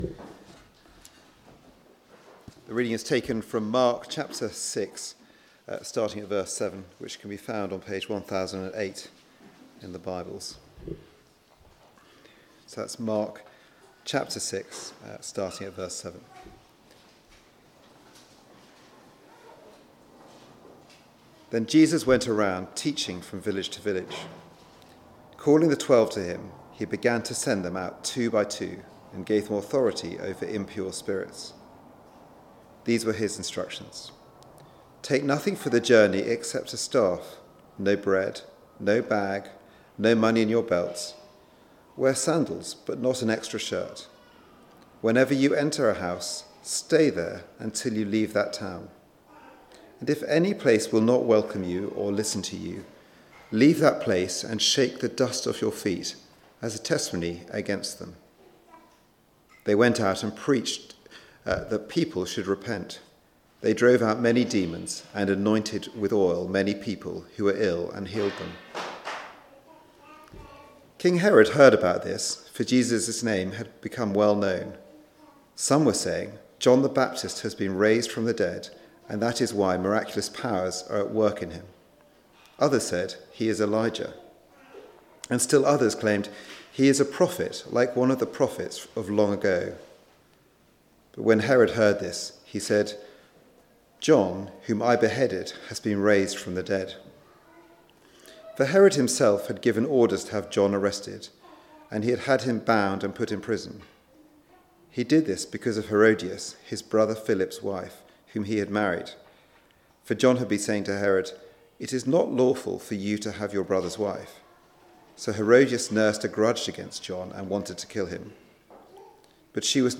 Follow the King Passage: Mark 6:7-29 Service Type: Weekly Service at 4pm « How will our time outside end?